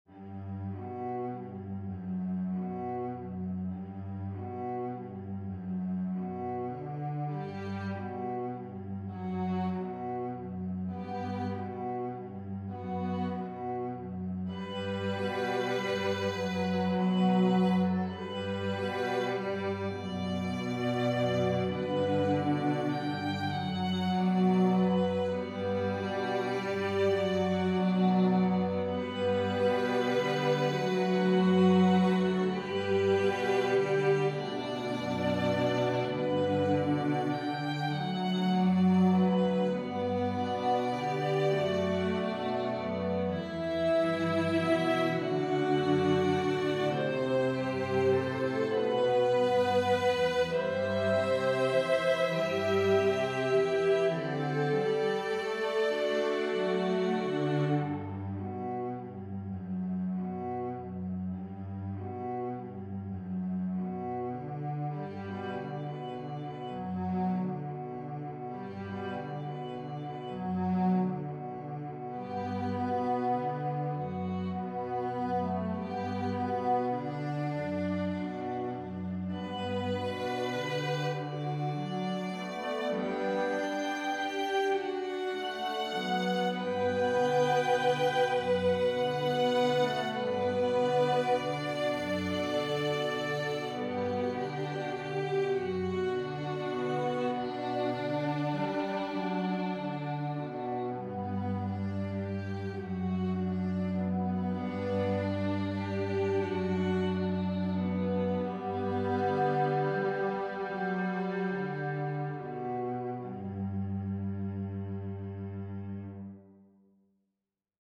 Listen to This Quartet I Wrote!
You may have to turn your speakers up.